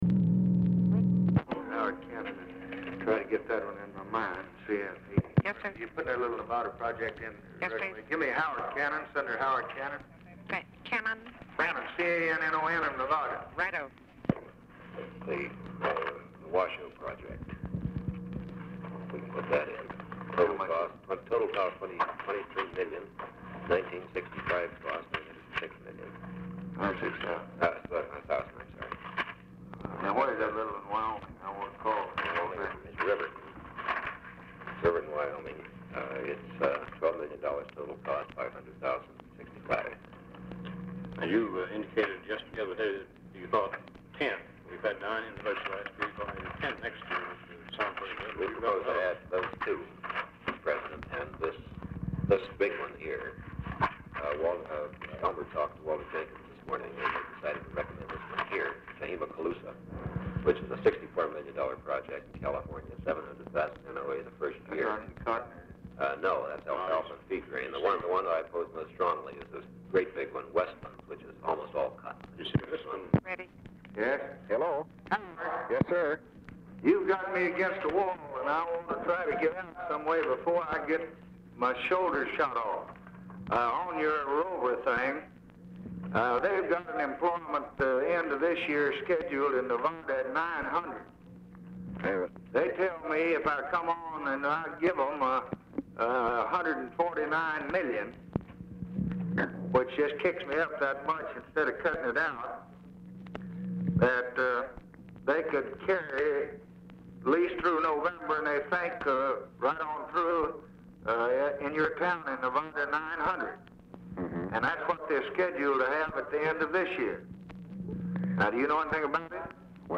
Telephone conversation # 589, sound recording, LBJ and HOWARD CANNON
1:10 OFFICE CONVERSATION PRECEDES CALL; LBJ IS MEETING WITH ELMER STAATS, KERMIT GORDON AT TIME OF CALL; GORDON ALSO SPEAKS WITH CANNON
Format Dictation belt
TELEPHONE OPERATOR, OFFICE CONVERSATION, KERMIT GORDON